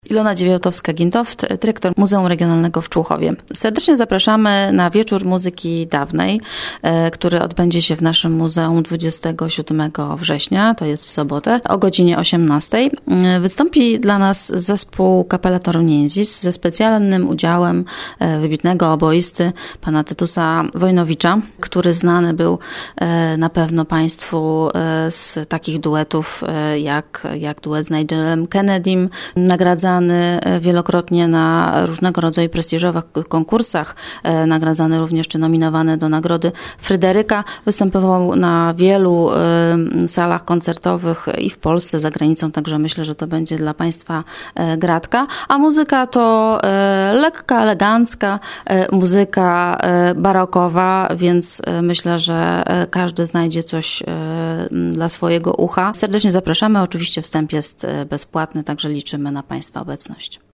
W programie muzycznym znajdą się eleganckie i melodyjne brzmienia wieczorowej muzyki z okresu XVII i XVIII wieku, autorstwa największych kompozytorów okresu baroku.